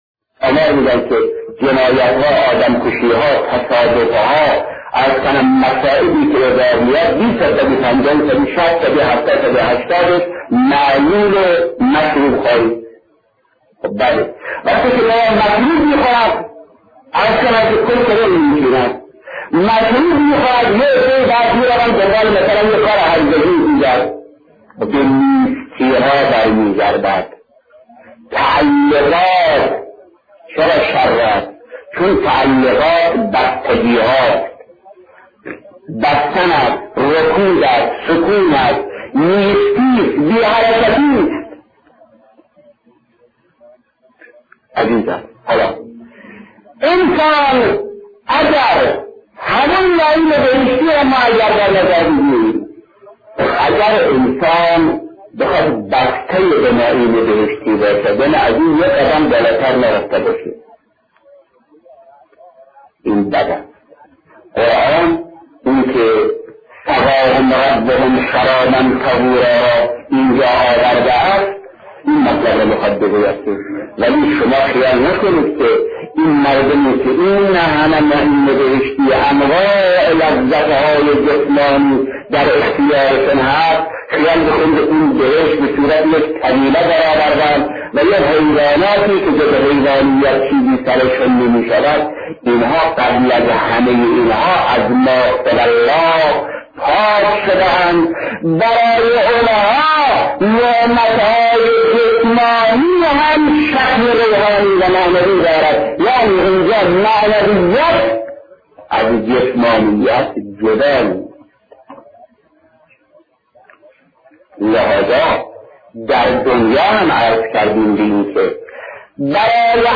شهید مطهری درباره نعمت های بهشتی صحبت می‌کند شماره3. تفسیر آیات سوره انسان. / صدا غیر واضح.